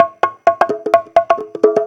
Conga Loop 128 BPM (13).wav